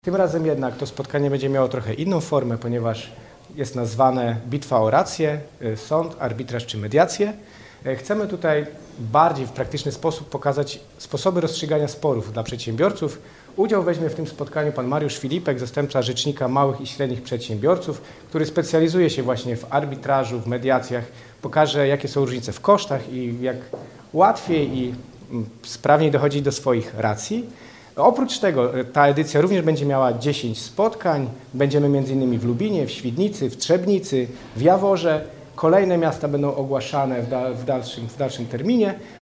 Organizatorzy zapowiadają, że kolejna edycja projektu będzie jeszcze lepiej dopasowana do potrzeb przedsiębiorców. – Druga edycja Dolnośląskich Liderów Biznesu ruszy 17 lutego we wrocławskim TriQube – mówi Michał Rado, Wicemarszałek Województwa Dolnośląskiego.